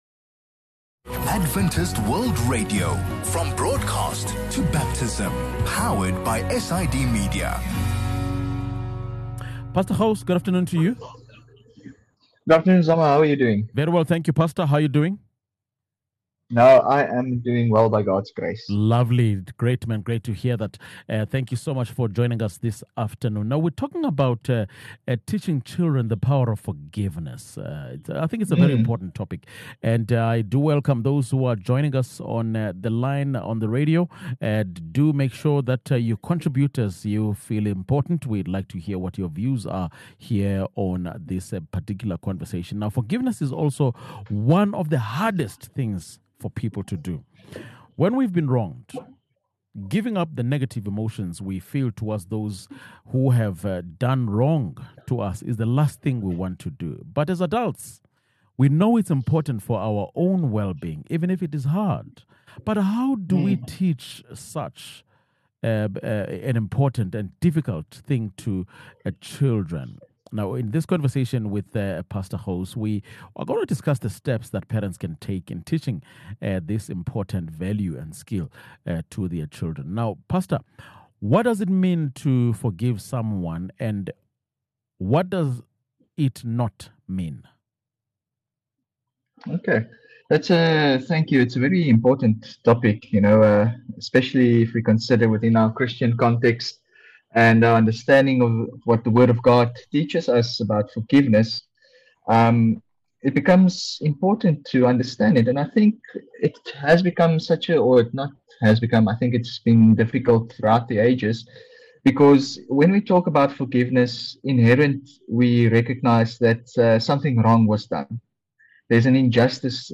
In this conversation, we will discuss the steps parents can take in teaching the concept of forgiveness to their children.